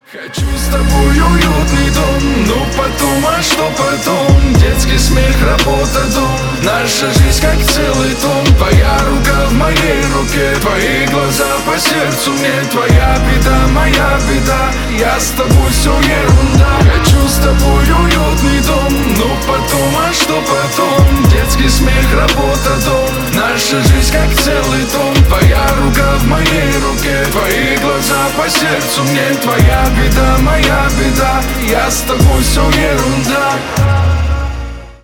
поп , романтичные